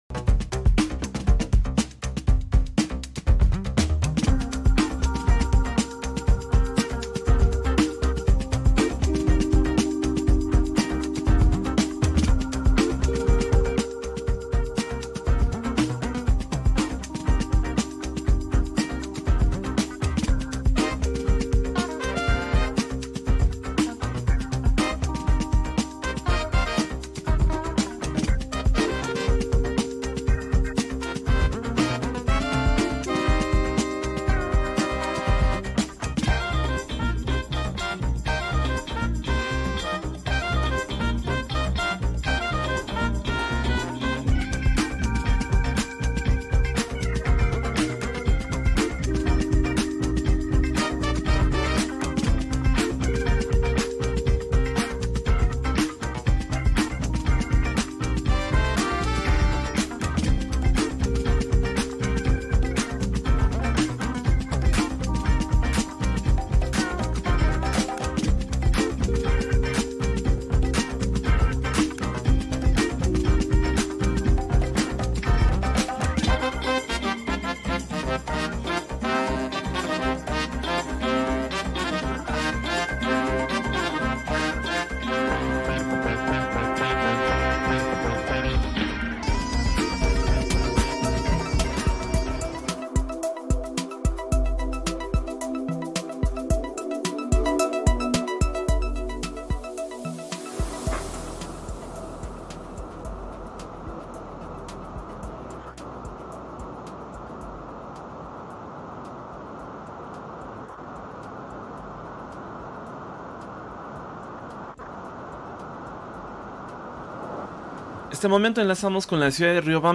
Escuche la entrevista completa con otras interesantes reflexiones.